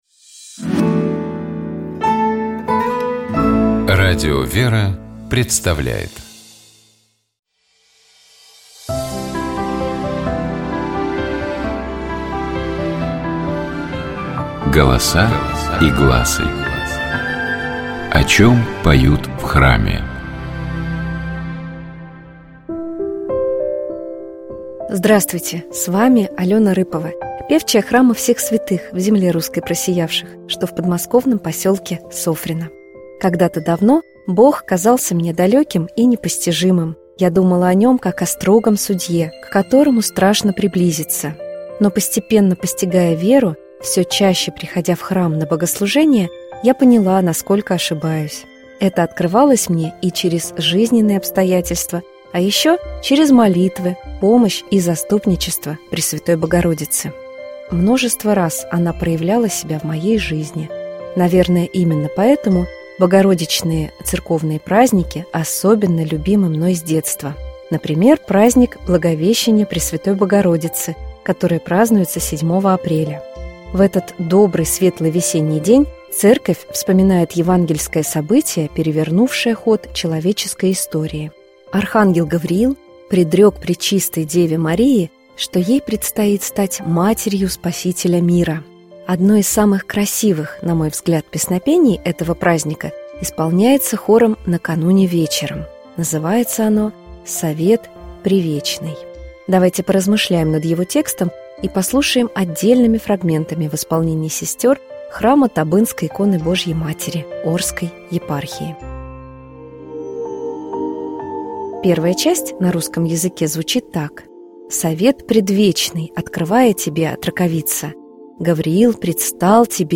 Давайте поразмышляем над его текстом и послушаем отдельными фрагментами в исполнении сестёр храма Табынской иконы Божией Матери Орской епархии.
Давайте послушаем песнопение «Совет превечный» полностью в исполнении сестёр храма Табынской иконы Божией Матери.